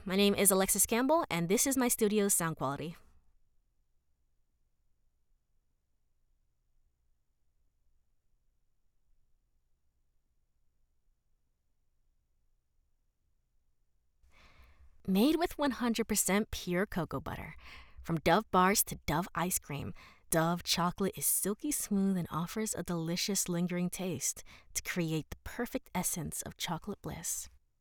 Commercial
Female
Approachable, Bubbly, Conversational, Cool, Streetwise, Young, Bright, Character, Children, Confident, Energetic, Engaging, Friendly, Natural, Smooth, Versatile, Warm
General American [native], New York [native], Caribbean (Jamaican/Grenadian), RP British, African (Zulu), American Southern (Alabama, etc.)
Microphone: Neumann TLM 103, Synco D2 shotgun microphone